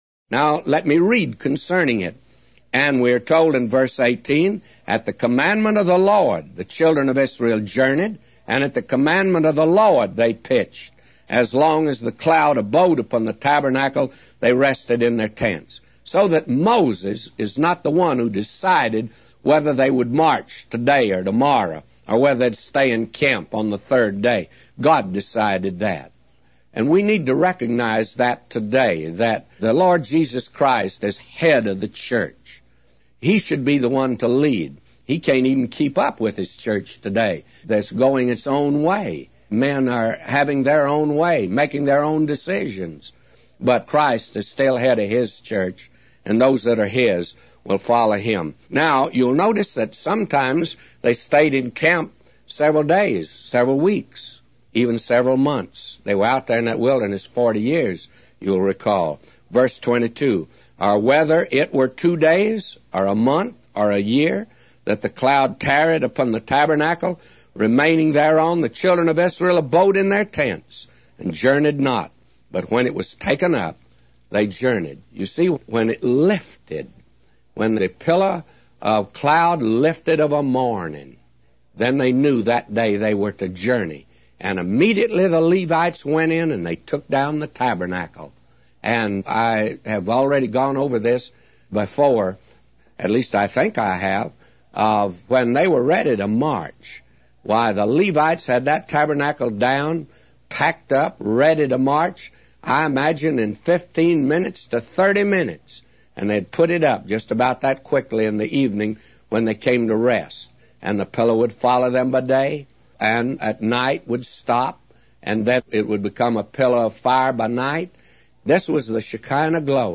A Commentary By J Vernon MCgee For Numbers 9:1-999